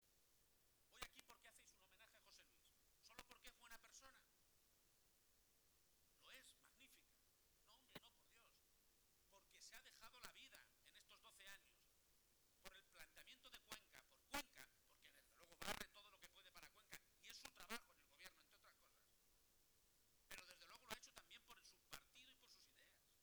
Durante la inauguración del Congreso Provincial del PSOE de Cuenca, que se celebra este fin de semana
Cortes de audio de la rueda de prensa